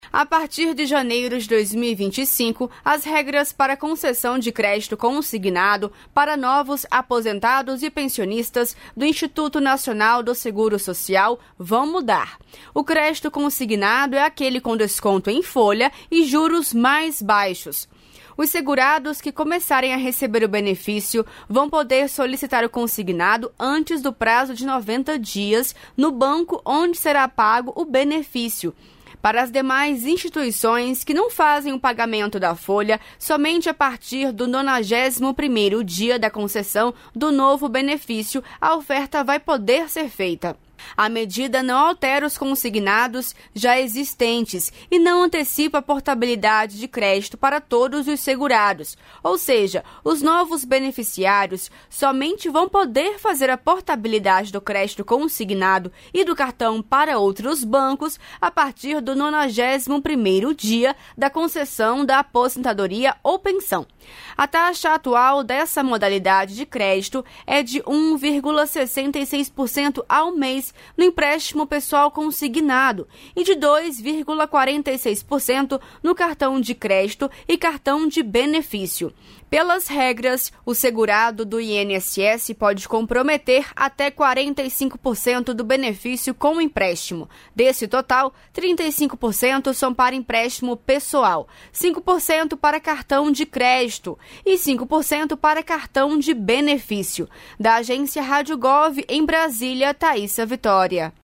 Medida não altera os consignados já existentes e não antecipa a portabilidade de crédito para todos os segurados; saiba mais no boletim.